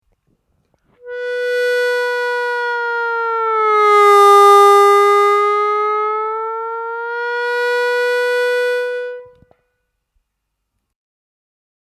На третьем отверстии плавно понижать ноту до самого дна, возвращаясь затем на чистую ноту.
Бенд на третьем отверстии